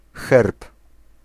Ääntäminen
Ääntäminen Tuntematon aksentti: IPA: /xɛrp/ Haettu sana löytyi näillä lähdekielillä: puola Käännös 1. escudo de armas {m} 2. escudo {m} Suku: m .